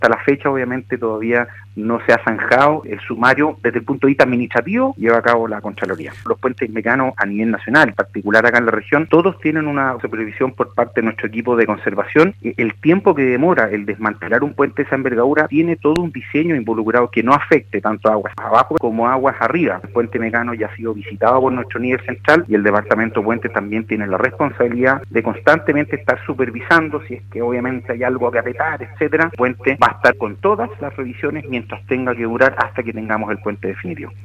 En conversación con el programa Haciendo Ciudad de Radio Sago, el Seremi del Ministerio de Obras Públicas, James Fry abordó el desplome de este puente el 23 de junio del 2018 y que dejó a una persona fallecida y seis heridos, además de la destitución de diversos cargos del Ministerio y el inicio de acciones legales.